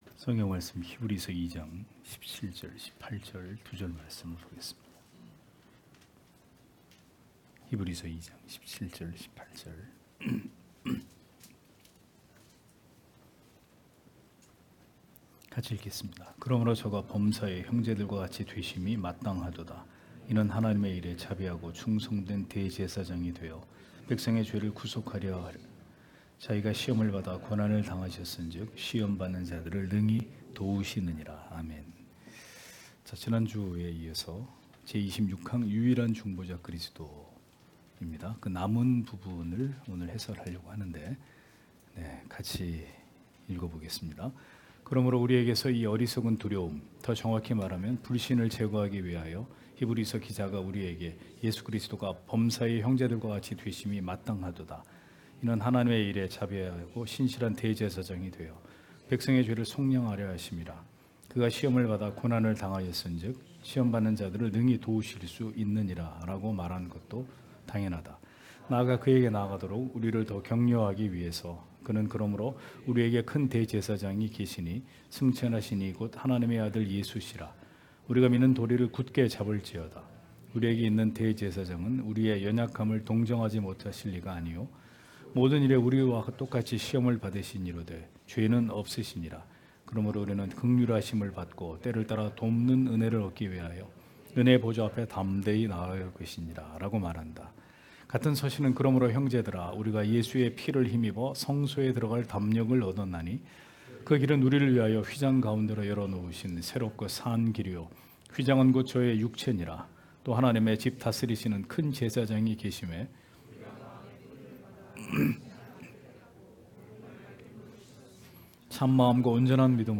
주일오후예배 - [벨직 신앙고백서 해설 30] 제26항 유일한 중보자 그리스도(2) (히2장17-18절)